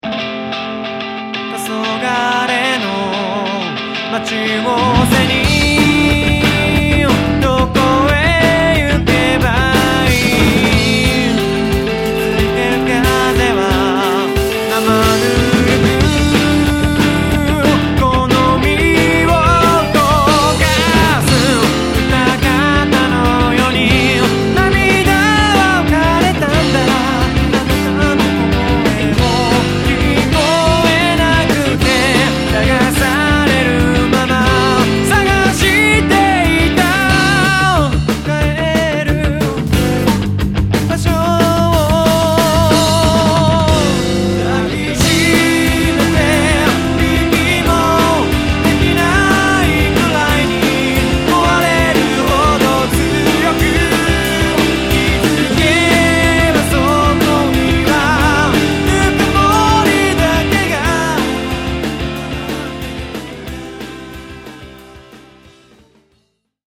パワーポップなロックバンドとでもいいましょうか。
サウンドがハードな分、ボーカルの存在感にかけるような気がする。
あと、かなり歪みが気になります。